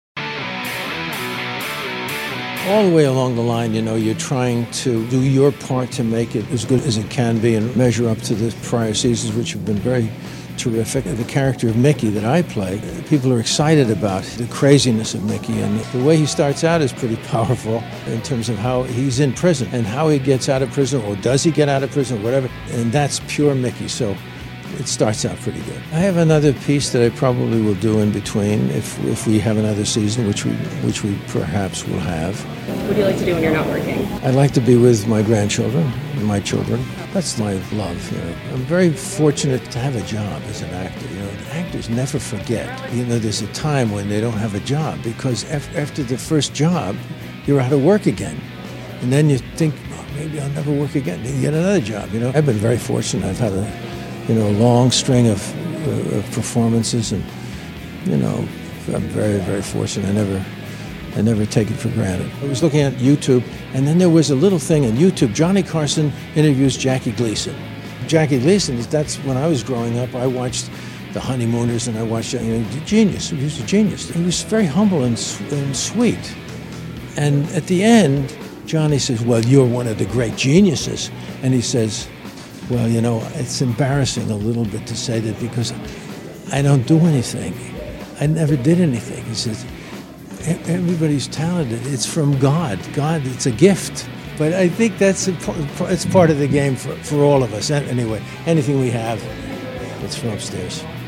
Native New Yorkers Jon Voight and Liev Schreiber premiered Ray Donavon season 6 at Tribeca TV Festival this weekend. The crime drama series moves from Los Angeles to the streets of New York for the upcoming 12-episodes. Oscar-winner, Jon Voight gave us a glimpse of what’s in store for his character Mickey Donovan.